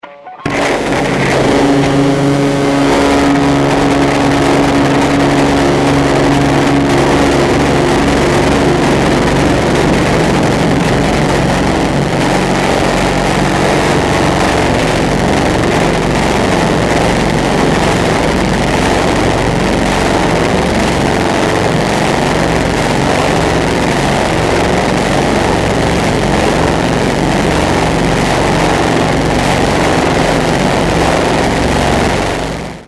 Solo guitar improvisations, variously layered
Gibson ES-335 guitar
I also used an e-bow and A/DA Flanger.